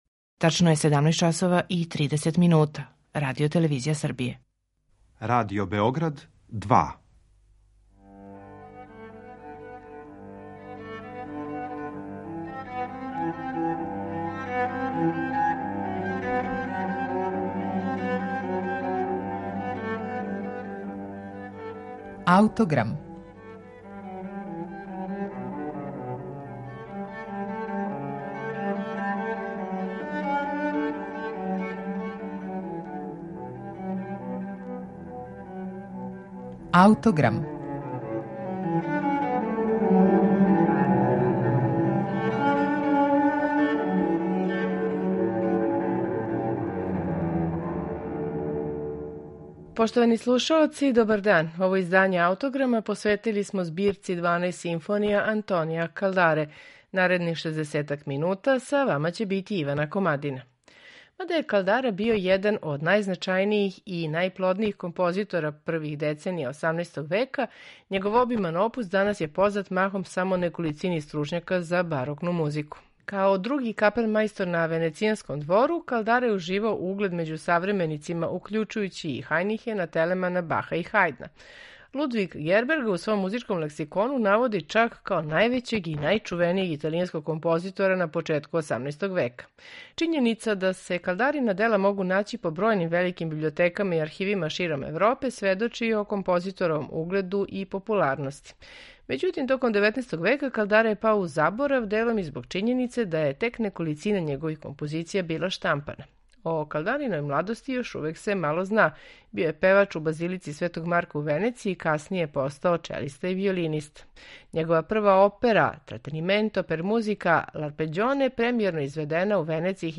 Мада су ове Калдарине симфоније скромних димензија, оне представљају веома важну музичку збирку. Дванаест симфонија Антонија Калдаре којима смо посветили данашњи Аутограм слушаћете у интерпретацији ансамбла „Ars Antiqua Austria" и диригента Гунара Лецбора.